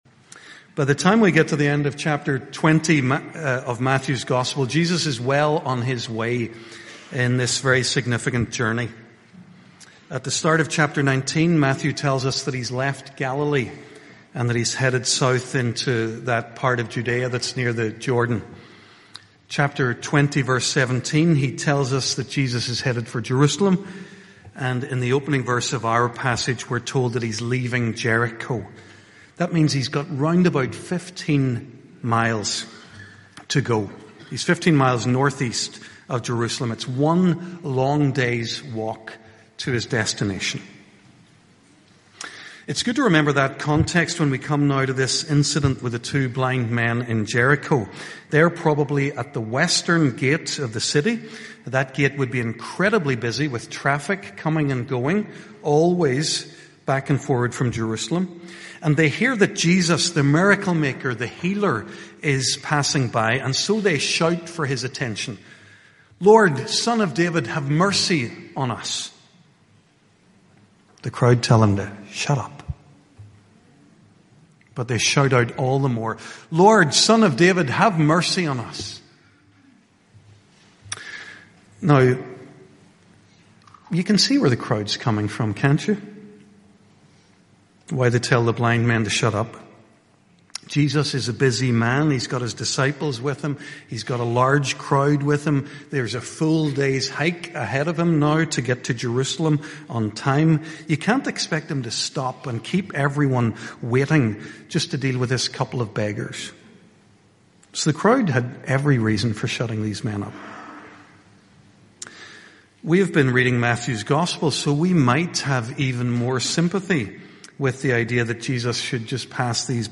HRPC-Sunday-Morning-Service-Sermon-1st-February-2026.mp3